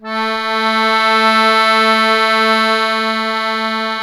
MUSETTE1.2SW.wav